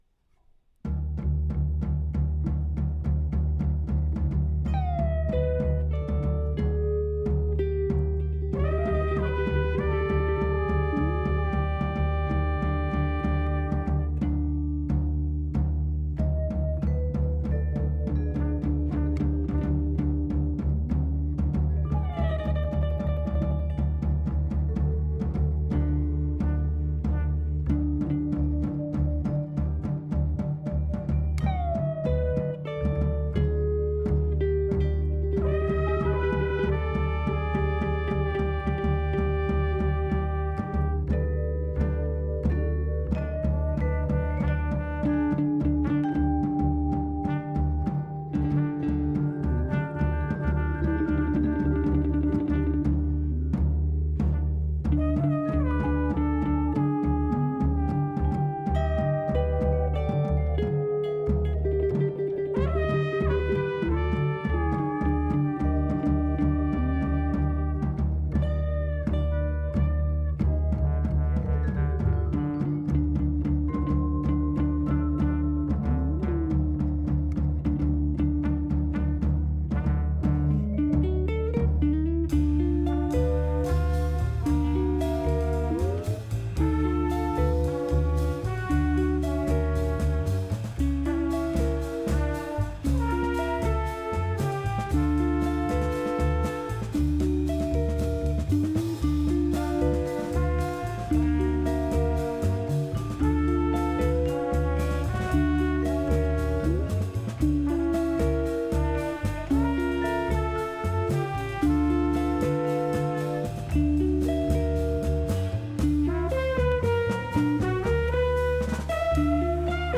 Dramatic and daringly intricate
trumpet
trombone
vibraphone
bass
drums
experimented with a piece for four horns instead of two